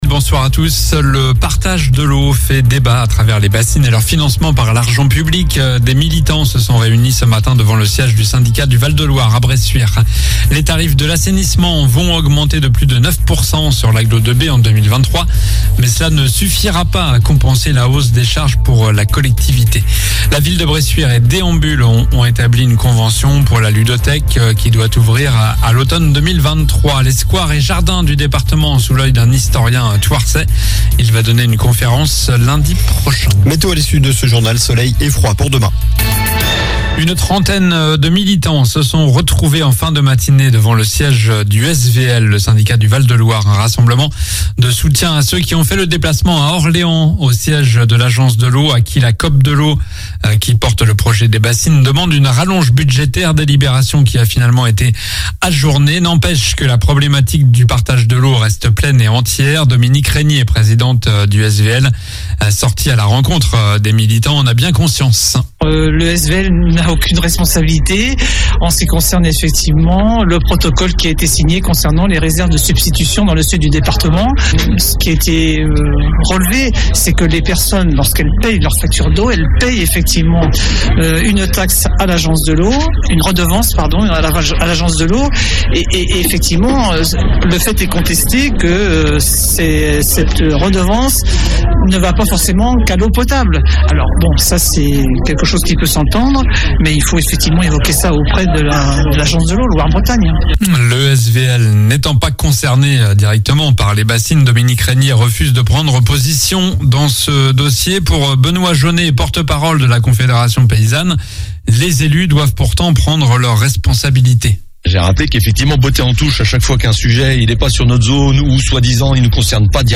Journal du jeudi 15 décembre (soir)